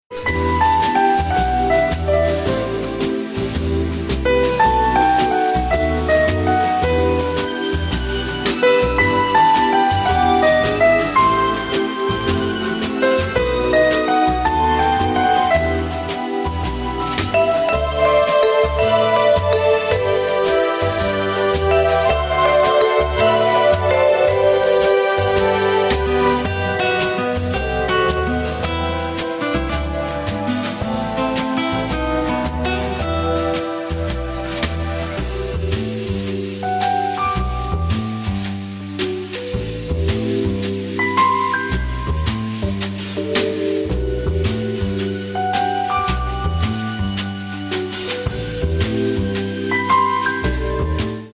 • Качество: 128, Stereo
саундтреки
без слов
красивая мелодия
скрипка
инструментальные
пианино
добрые
Smooth Jazz